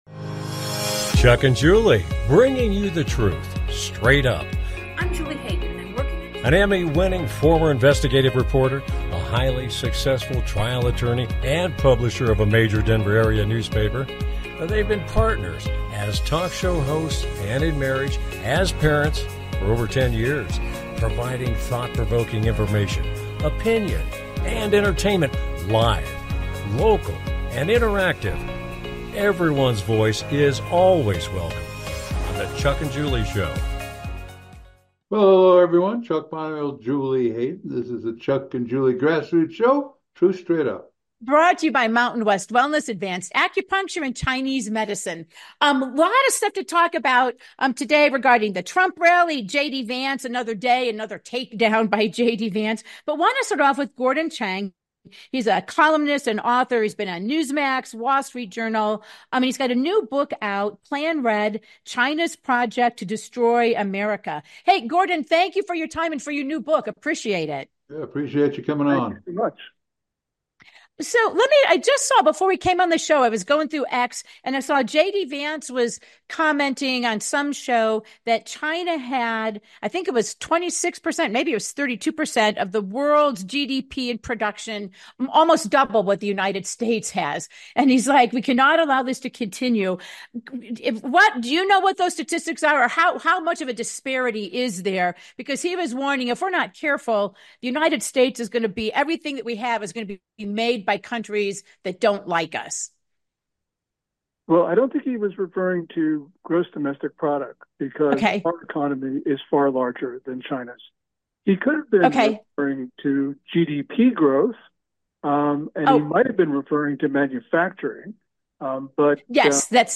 With Guest, Author, Gordon Chang, An expert on China - Another day another JD Vance takedown on mainstream media